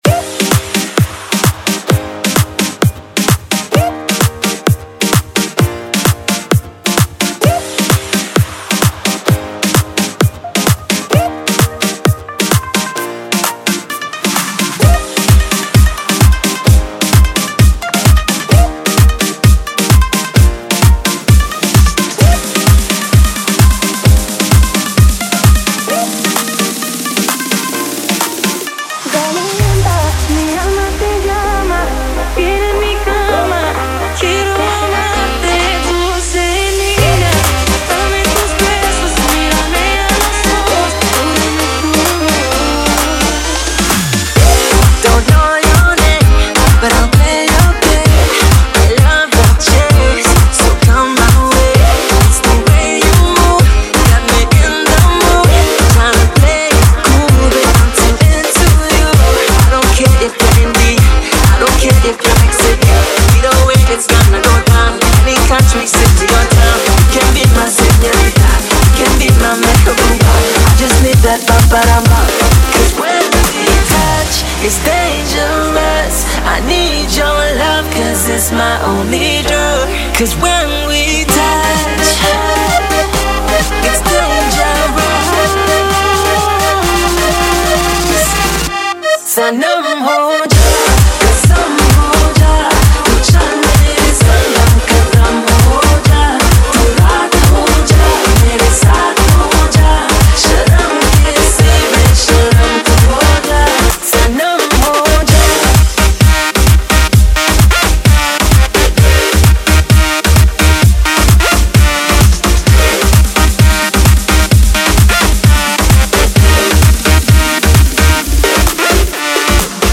DJ Remix Mp3 Songs